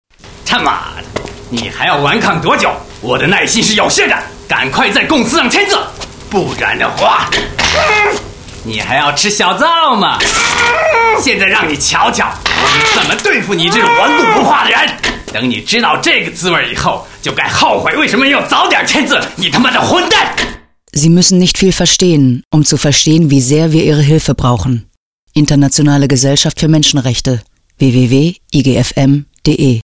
Hörfunk-Spots zur VR China